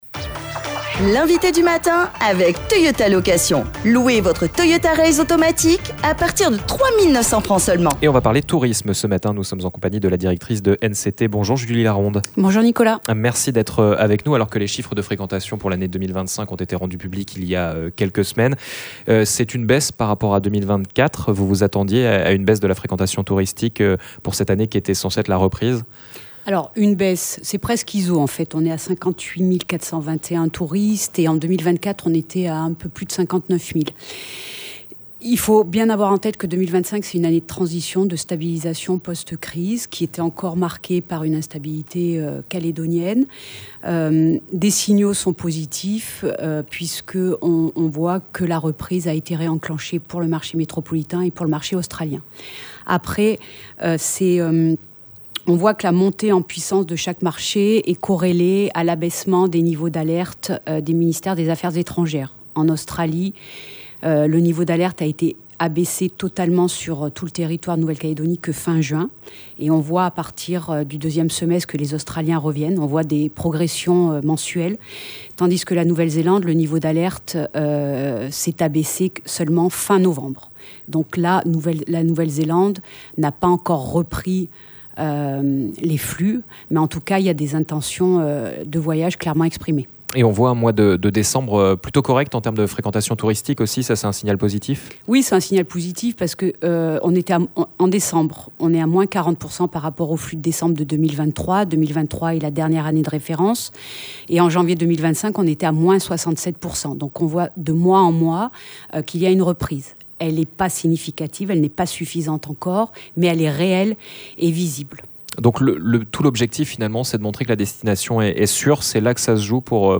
L'INVITÉ DU MATIN